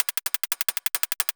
Index of /musicradar/ultimate-hihat-samples/175bpm
UHH_ElectroHatC_175-04.wav